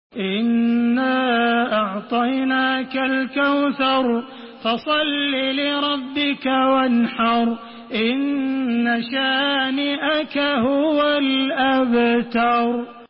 Surah Kevser MP3 by Makkah Taraweeh 1432 in Hafs An Asim narration.
Murattal Hafs An Asim